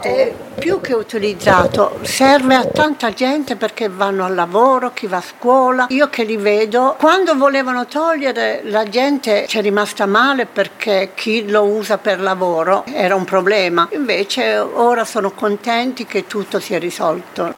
Un tema dibattuto da anni quello del traffico paralizzato ai passaggi a livello in città, le interviste qui sotto: